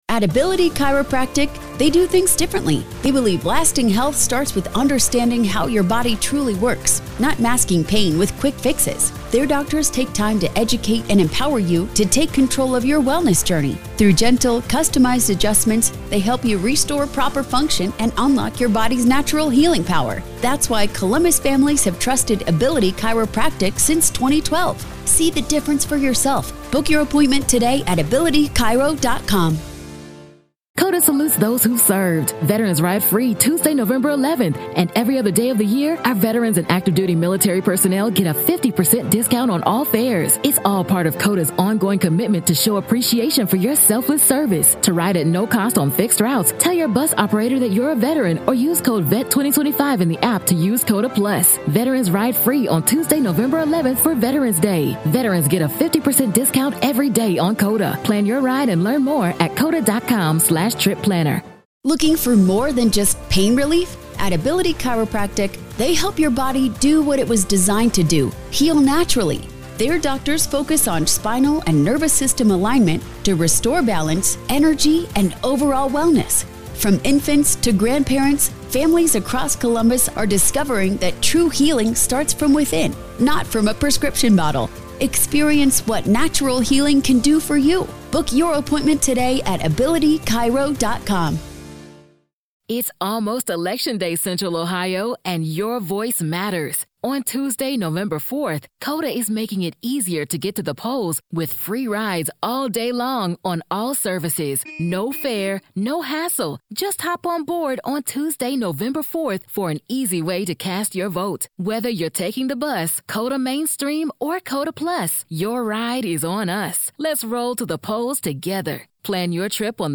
In this explosive interview